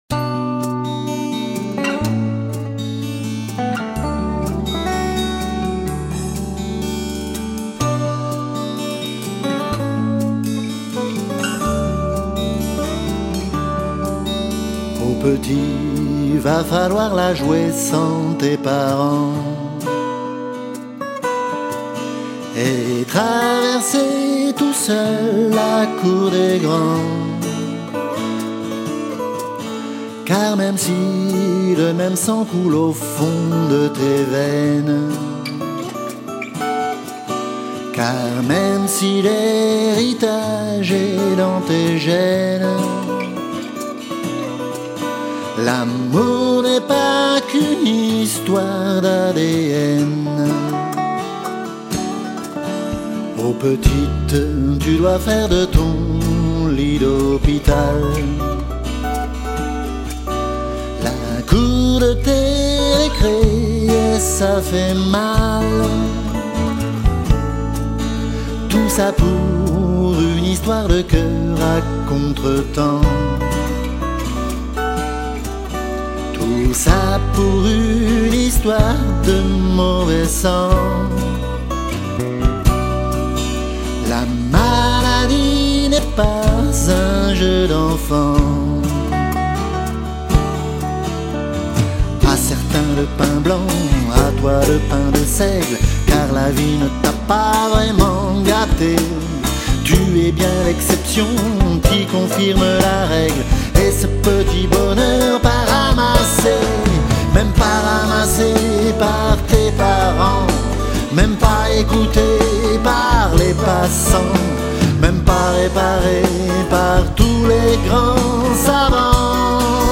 chante 13 titres originaux